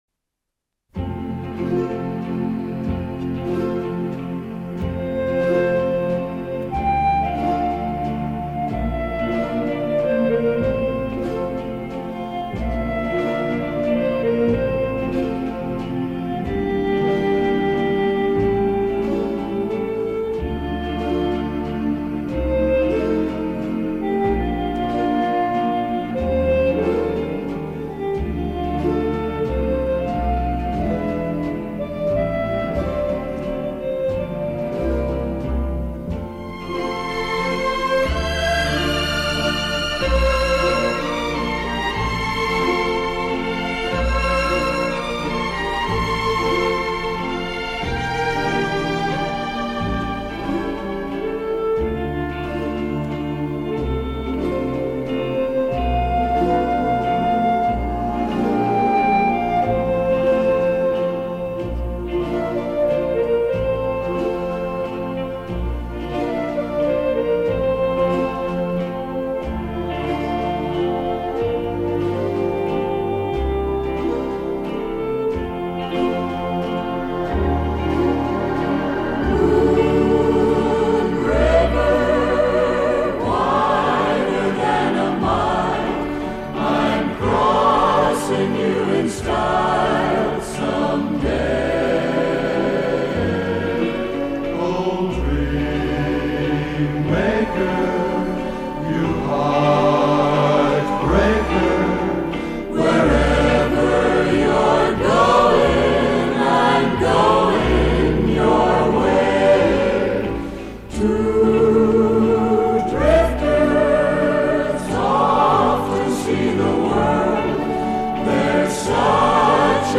Soundtrack, Jazz